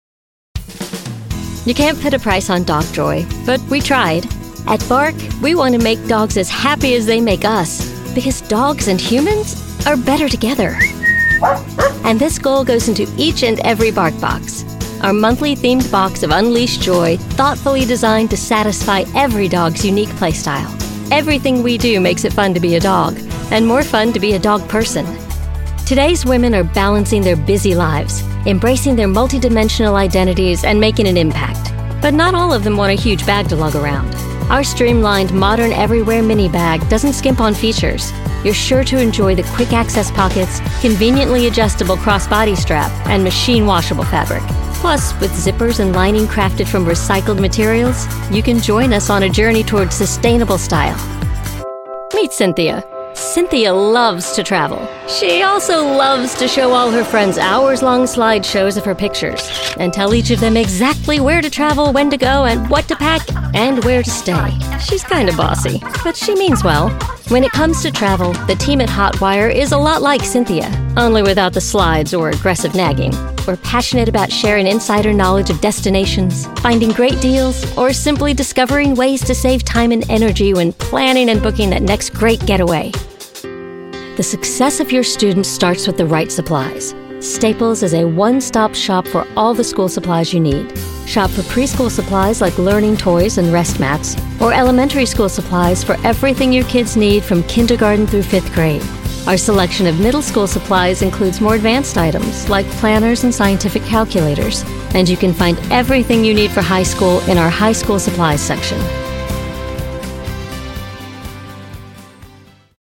Natuurlijk, Toegankelijk, Veelzijdig, Vriendelijk, Zakelijk
Explainer